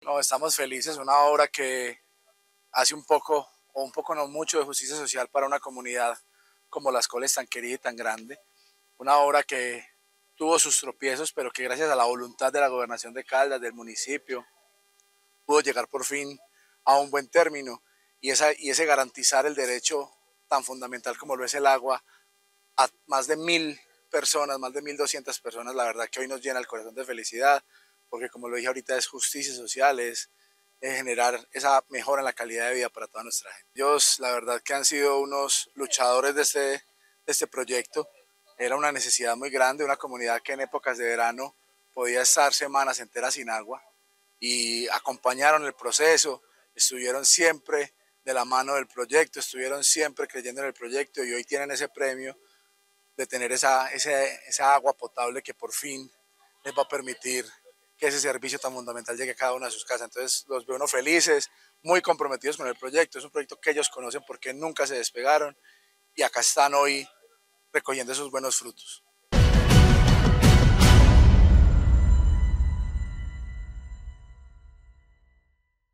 Juan Camilo Isaza González, alcalde de Pácora